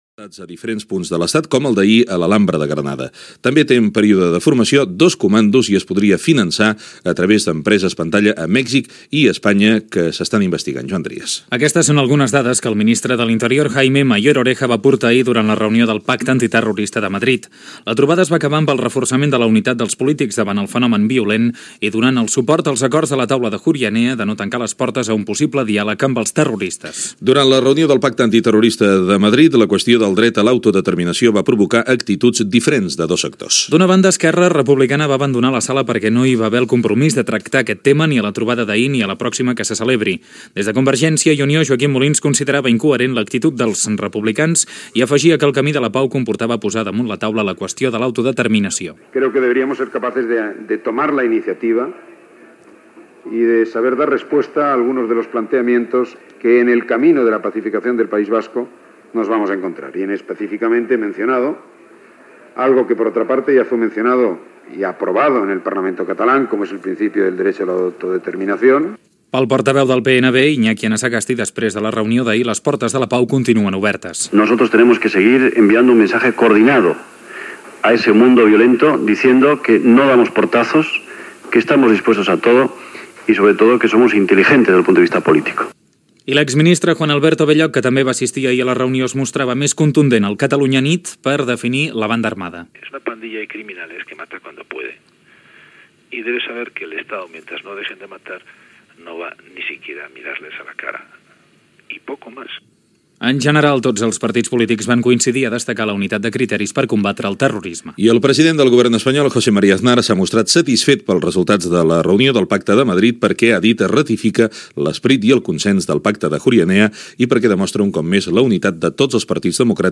Informació sobre la reunió del Pacte Antiterrorista de Madrid i entrevista al ministre de l'Interior, Jaime Mayor Oreja, sobre la situació de la lluita contra el terrorisme d'ETA.
Informatiu